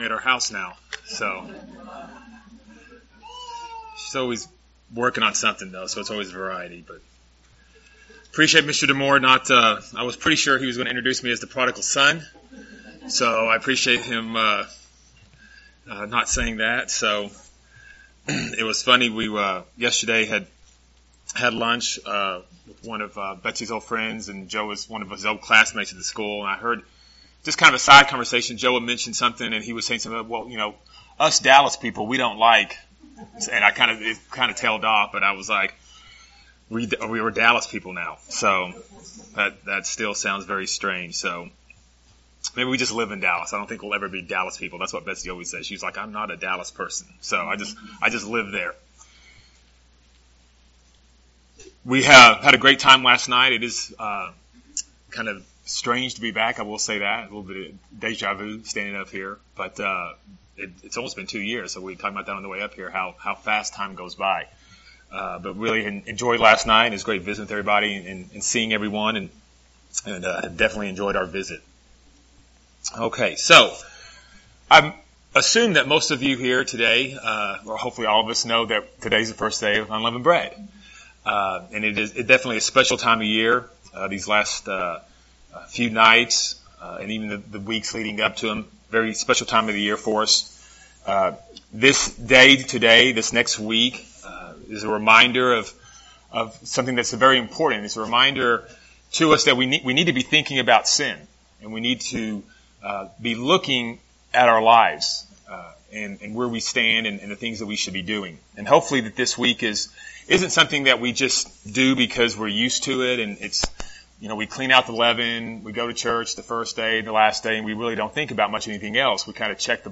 Holy Day Services Holy Days Christian beliefs Studying the bible?